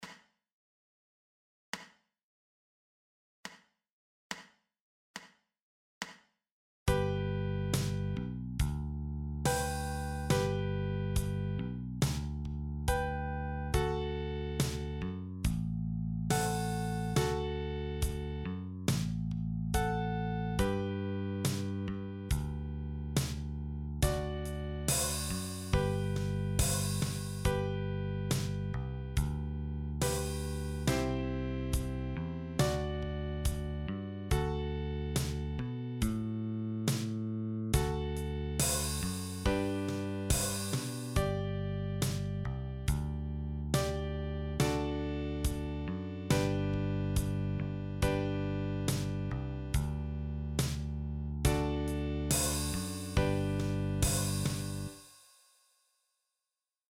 eBook Liederbuch für die chromatische Mundharmonika.
Soundbeispiel – Melodie & Band sowie Band alleine: